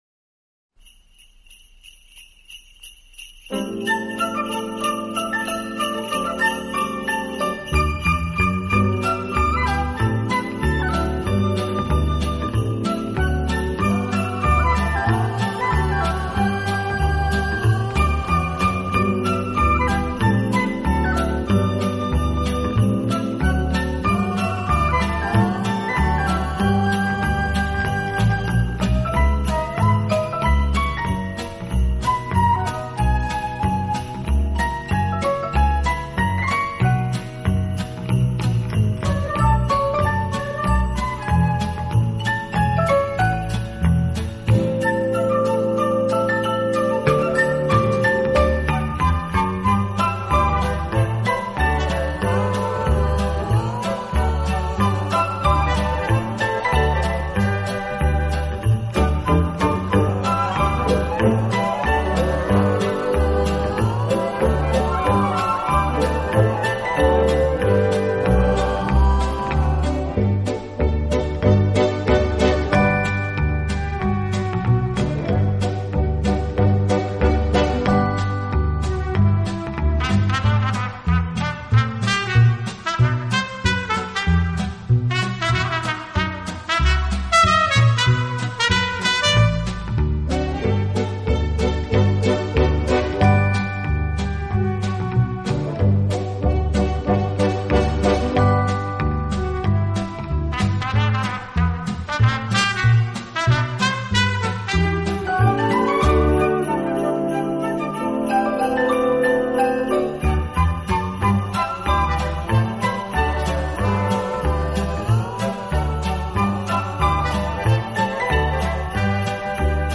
温情、柔软、浪漫是他的特色，也是他与德国众艺术家不同的地方。